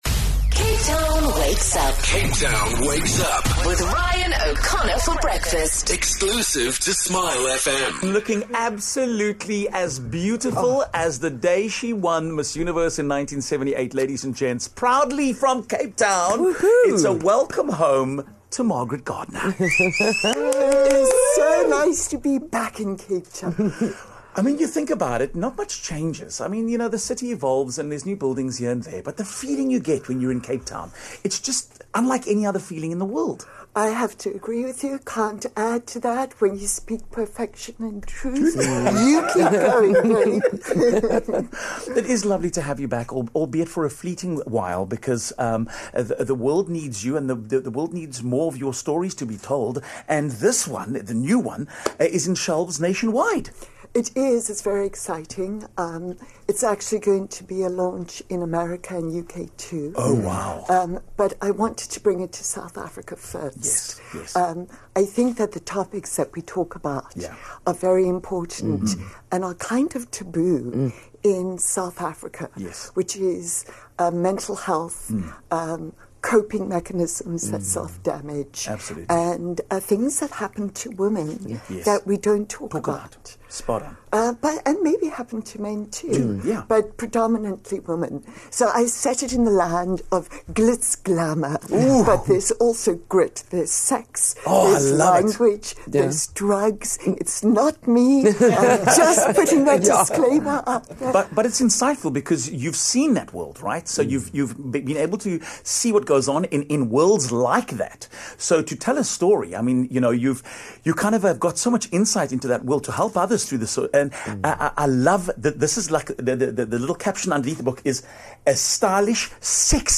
17 Mar SA's first Miss Universe Margaret Gardiner in studio
Margaret Gardiner is back in Cape Town and joined us in studio to chat about her new book.